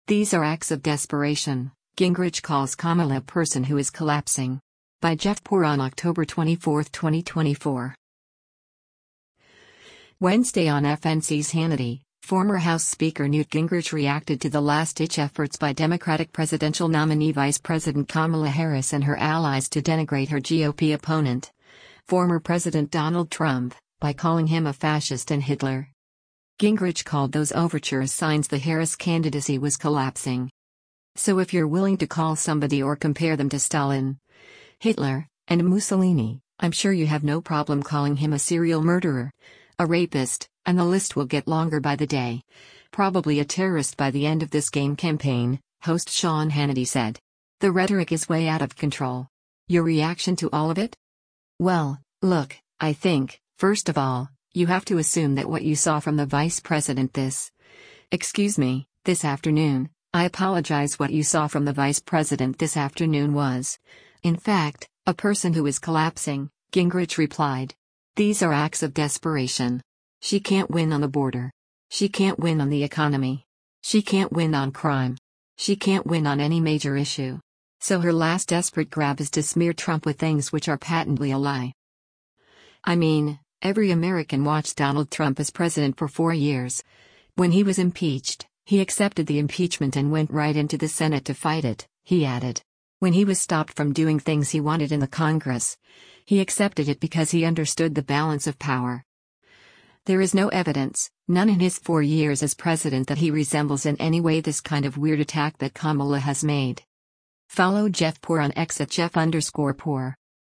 Wednesday on FNC’s “Hannity,” former House Speaker Newt Gingrich reacted to the last-ditch efforts by Democratic presidential nominee Vice President Kamala Harris and her allies to denigrate her GOP opponent, former President Donald Trump, by calling him a “fascist” and “Hitler.”